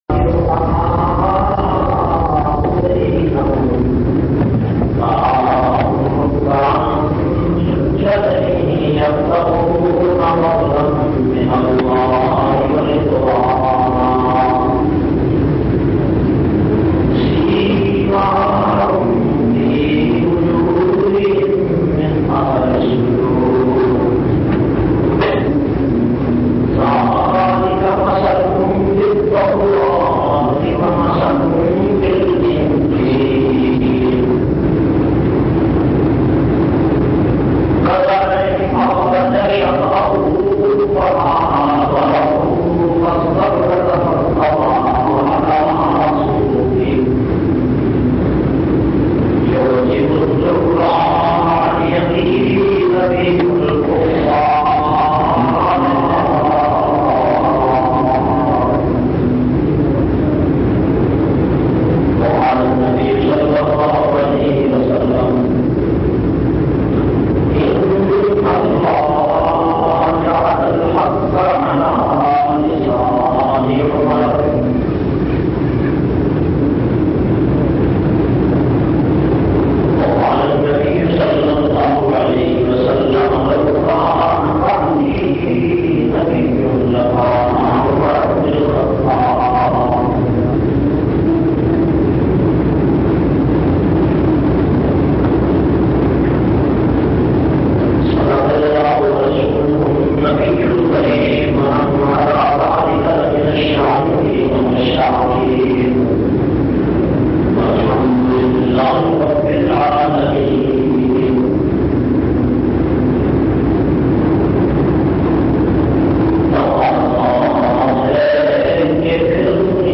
521- Sayyedena Umar Farooq Jumma Khutba Jamia Masjid Muhammadia Samandri Faisalabad.mp3